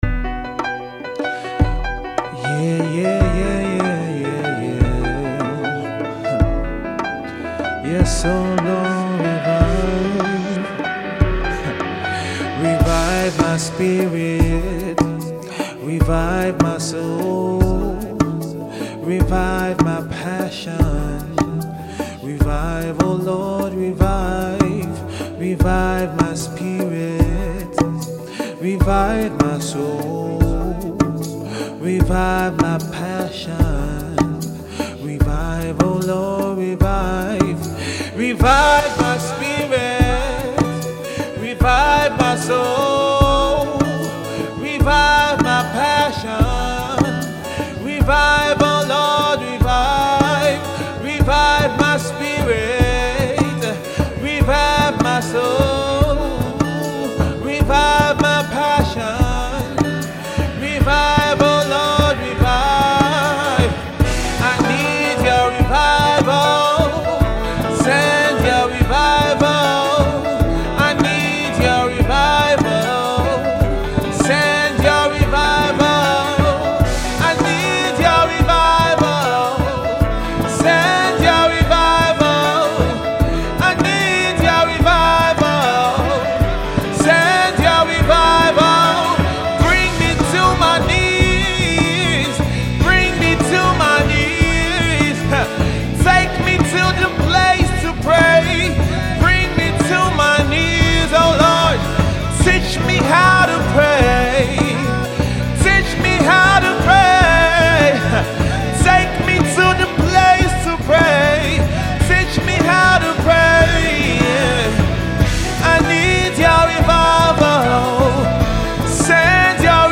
goes on a prayer session right in the vocal booth